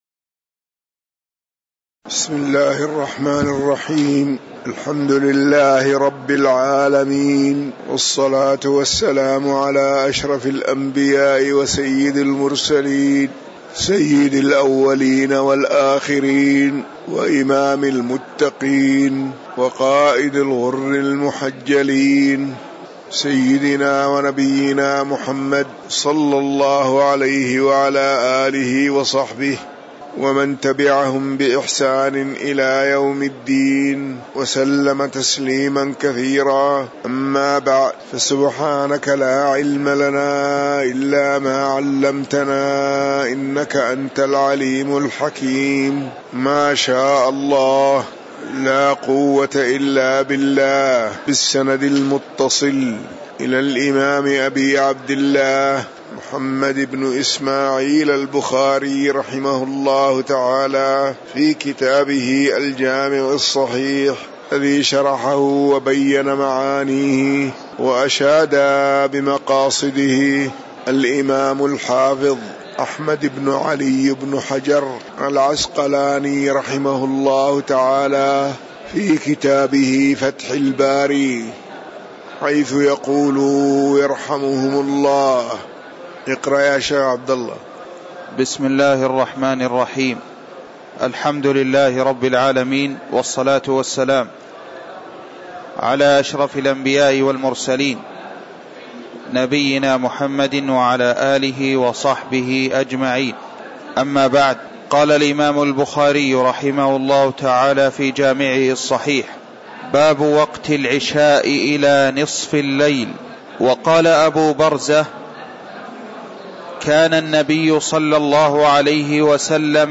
تاريخ النشر ١٦ ربيع الأول ١٤٤١ هـ المكان: المسجد النبوي الشيخ